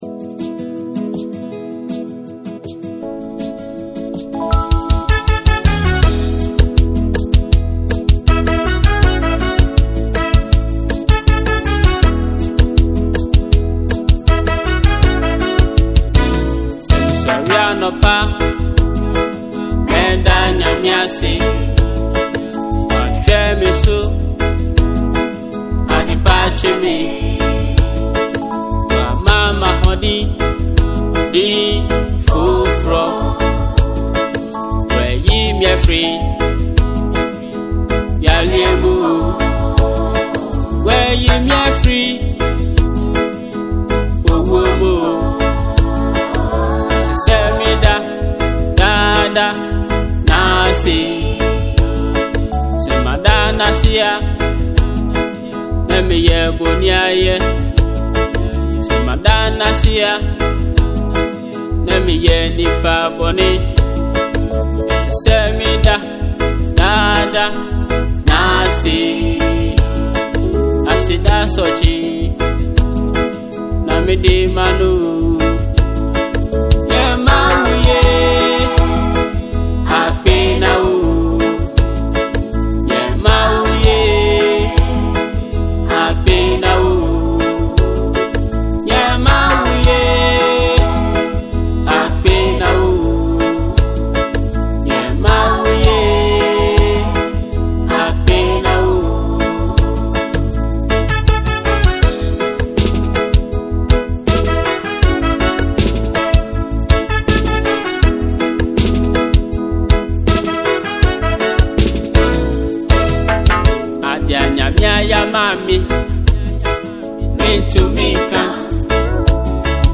cool tempo tune
soothing music